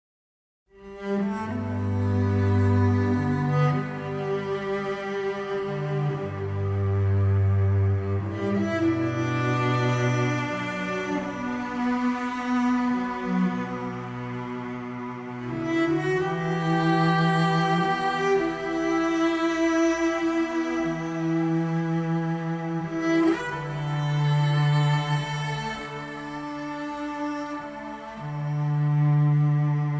Heavenly Violin & Cello Instrumentals